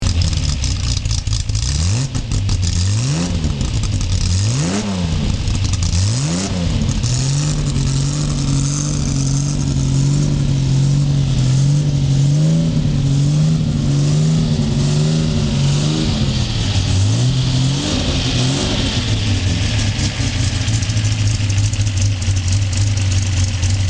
JWF Milano GT (1962) - Motorengeräusch
JWF_Milano_GT_Sound.mp3